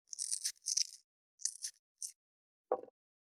499,桂むき,大根の桂むきの音切る,包丁,厨房,台所,野菜切る,咀嚼音,ナイフ,調理音,
効果音厨房/台所/レストラン/kitchen食材